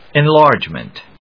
音節en・lárge・ment 発音記号・読み方
/‐mənt(米国英語), ˌeˈnlɑ:rdʒmʌnt(英国英語)/